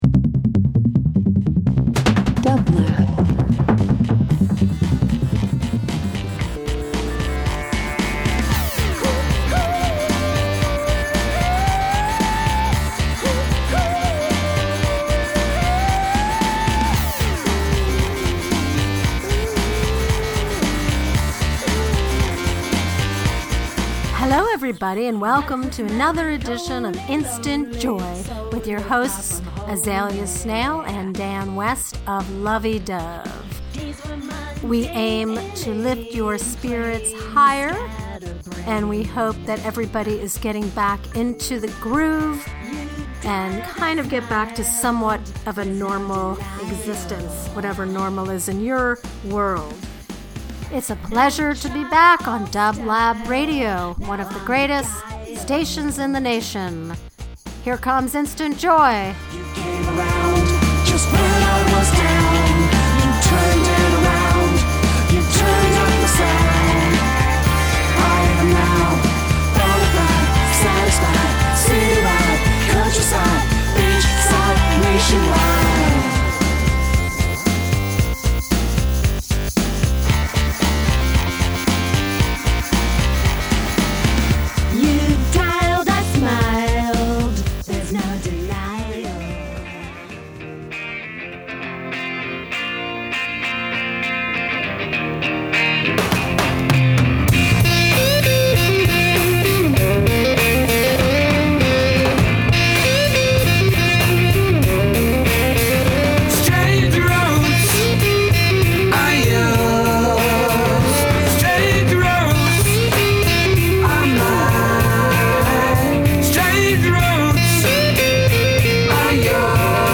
Garage Rock Psych Rock Soul